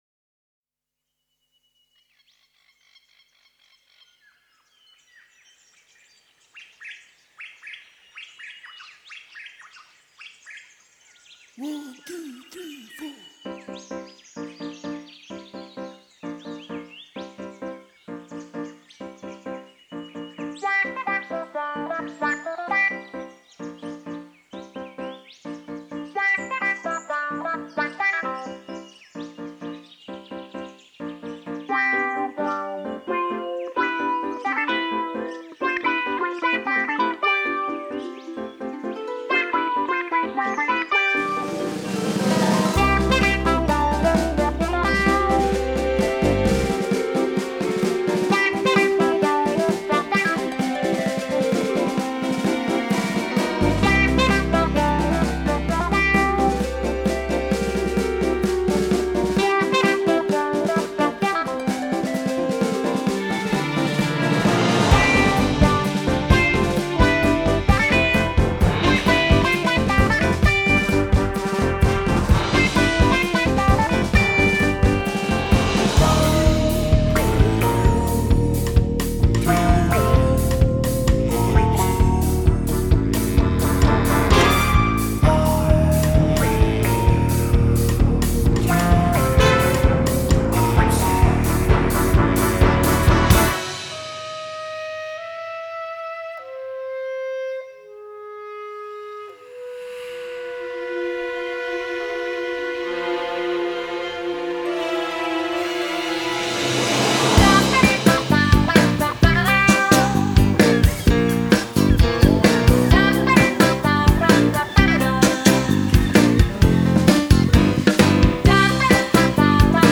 Guitars
Vocals
Keys, Saw
Bass
Drums
Saxes